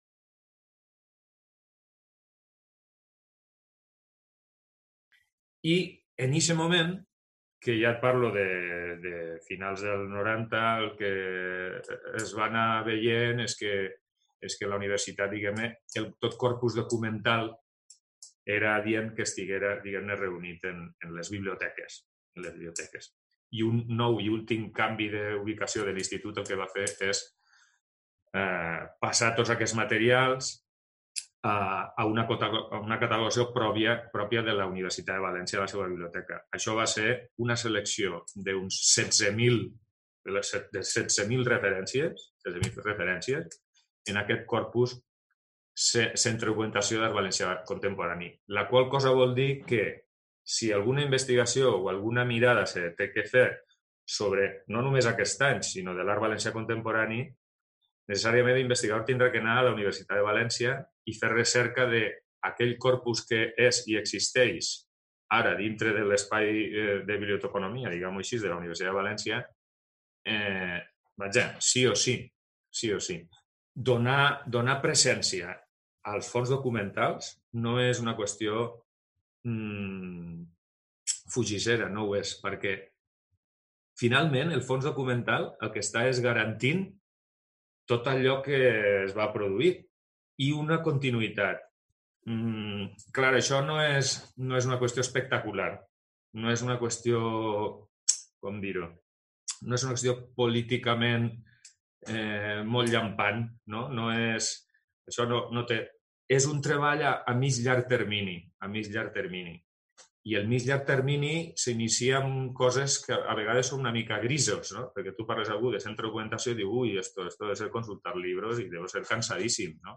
Part III [wm2tR6ZLNs4].mp3 Entrevista 2 14,24 MB MPEG Audio Compartir: